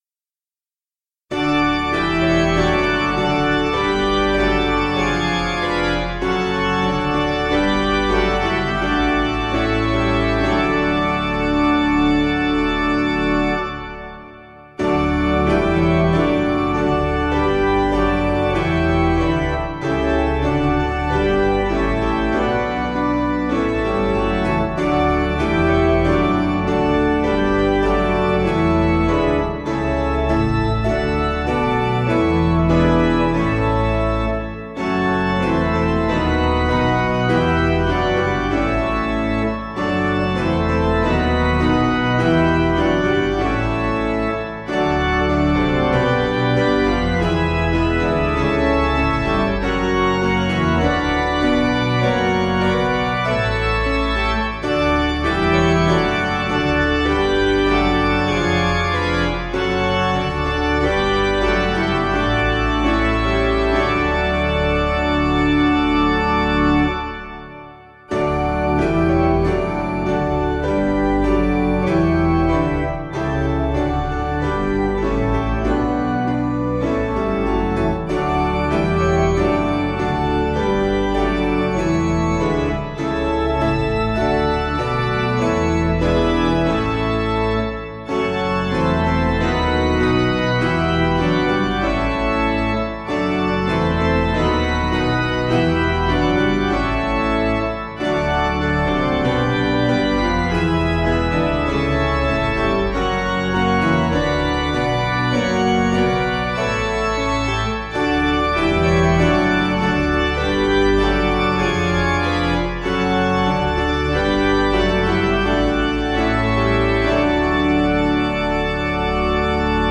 Meter: 7.7.7.7 D
Key: D Major